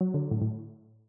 call_timeout-BCxXxRD8.mp3